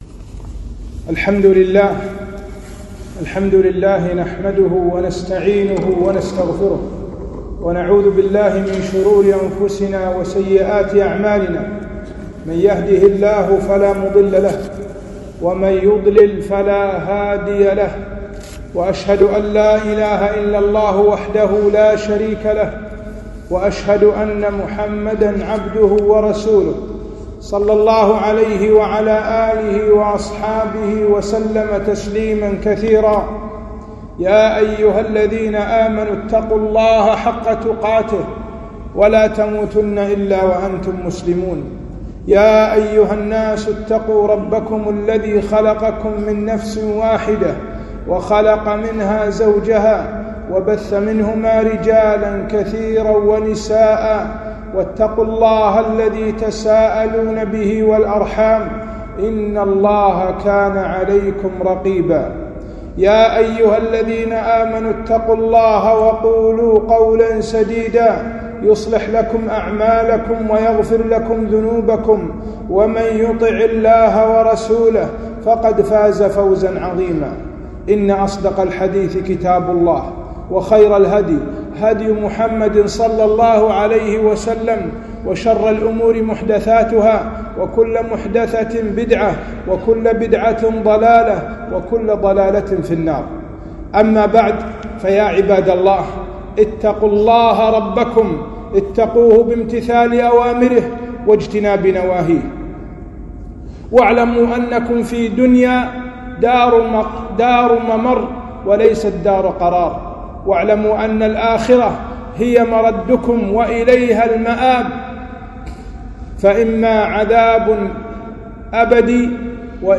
خطبة - وجوب الأسراع للأعمال الصالحة